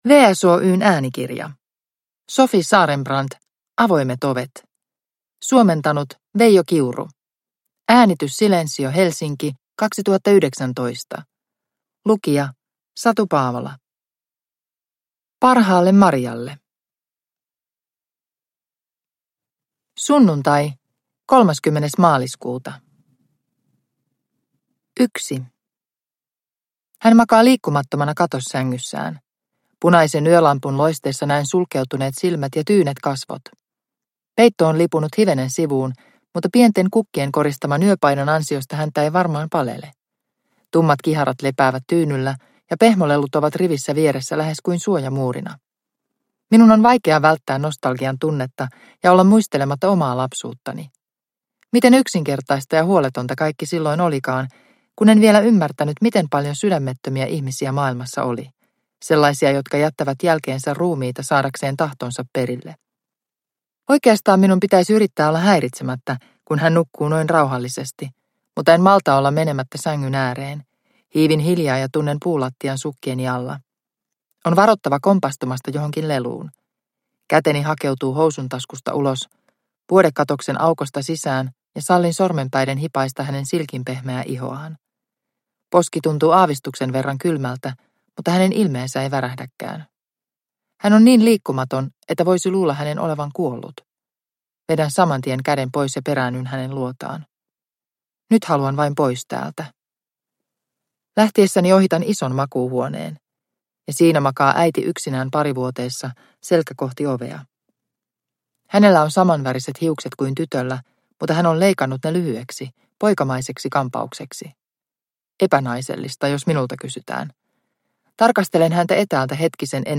Avoimet ovet – Ljudbok – Laddas ner